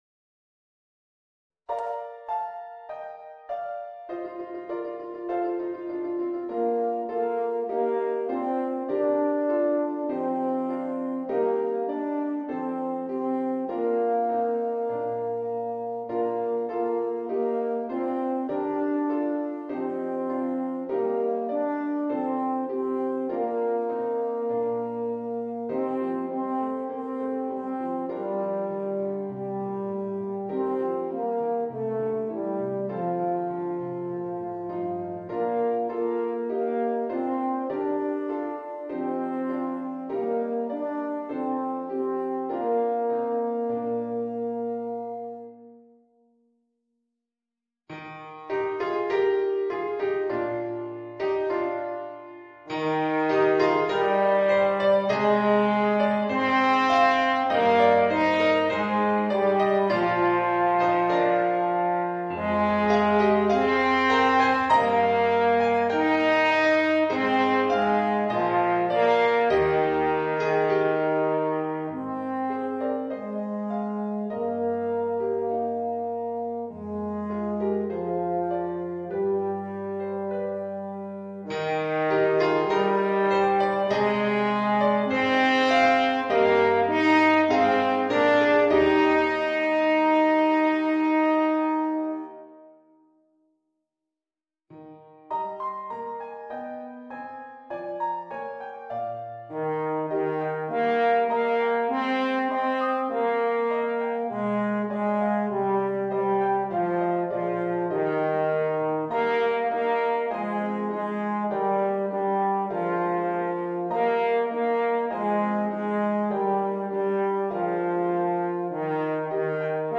Eb Horn